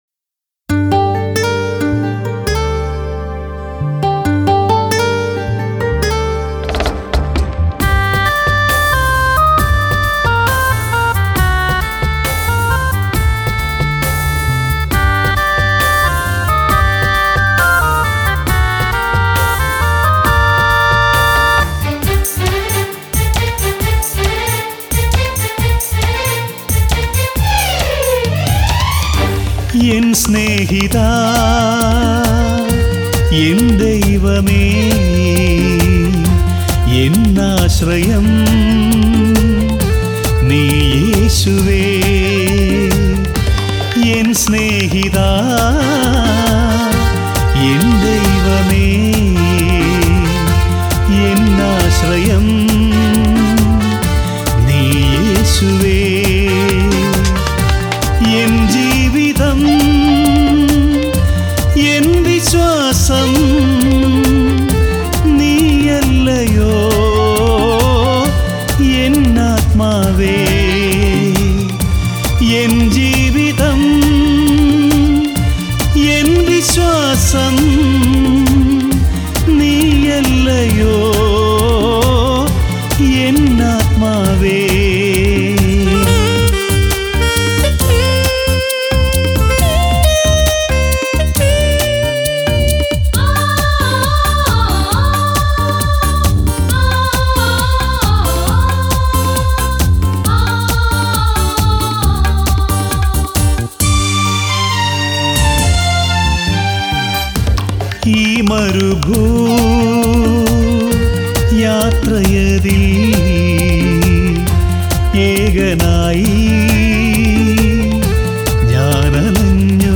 Keyboard Sequenced
Flute
Tabala & Percussuion